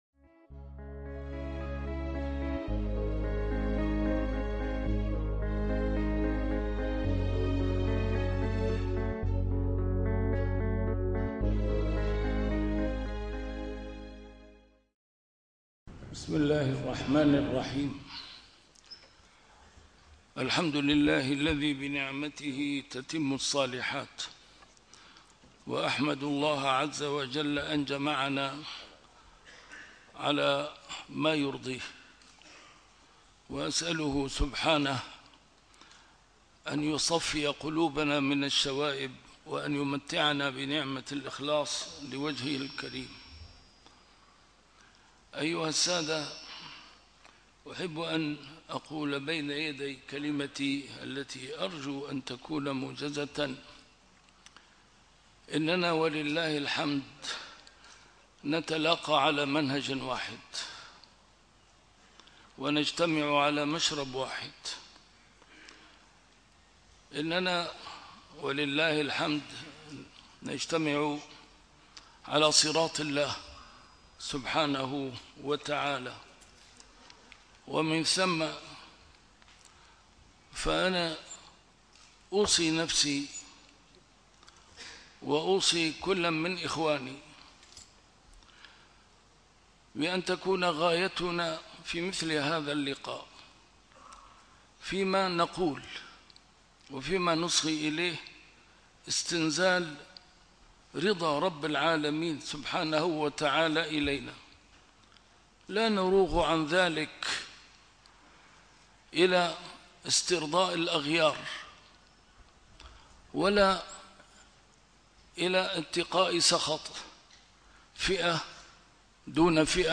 A MARTYR SCHOLAR: IMAM MUHAMMAD SAEED RAMADAN AL-BOUTI - الدروس العلمية - محاضرات متفرقة في مناسبات مختلفة - كلمة الإمام البوطي لعلماء سوريا في بداية الأزمة السورية عام 2011